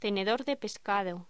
Locución: Tenedor de pescado
voz